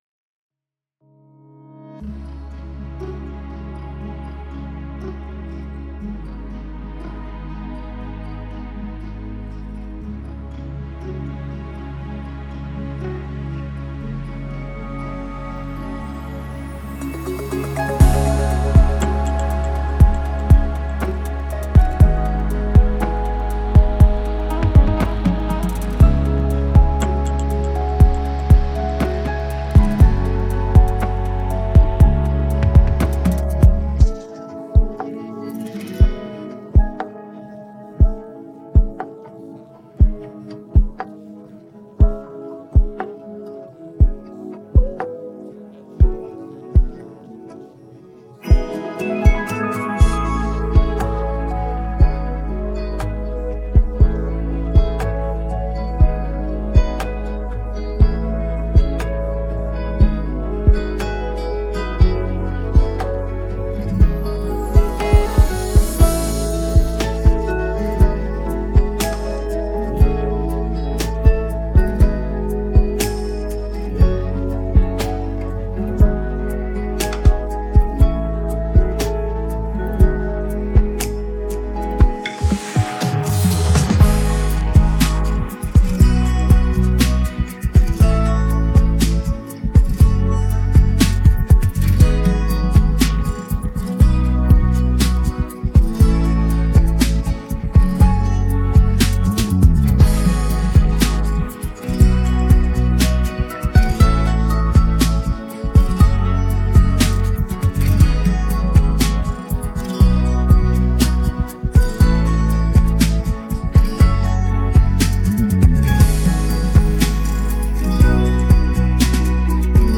بیت بدون صدا خواننده